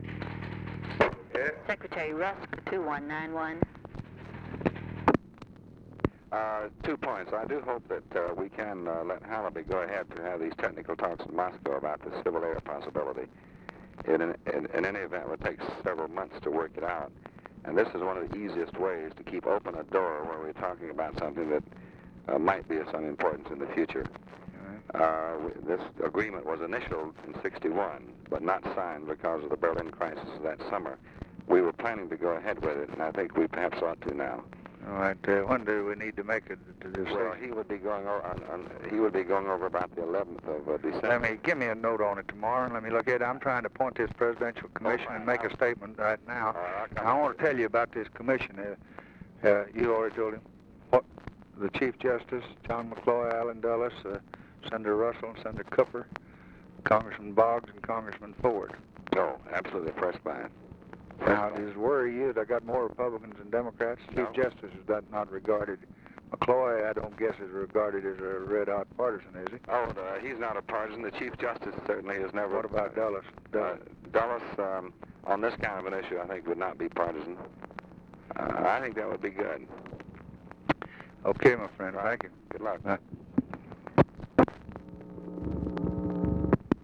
Conversation with DEAN RUSK, November 29, 1963
Secret White House Tapes